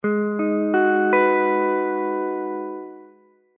ab_minor7.mp3